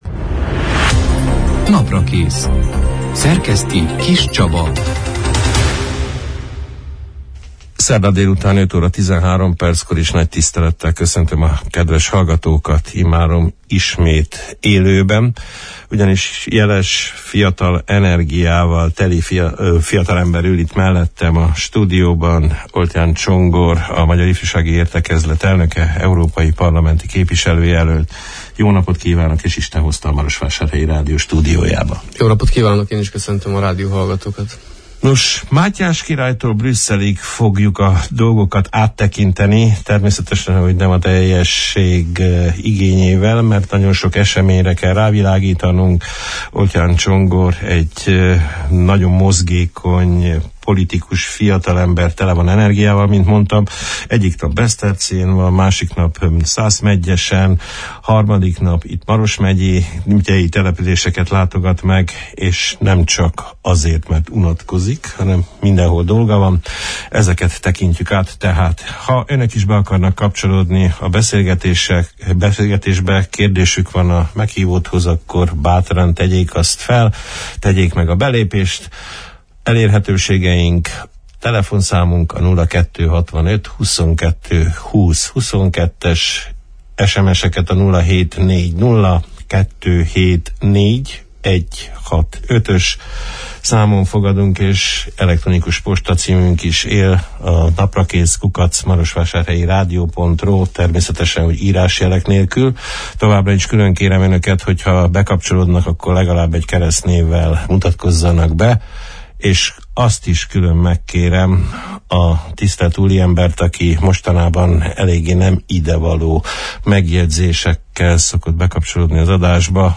beszélgettünk az április 10 – én, szerdán elhangzott élő Naprakész műsorban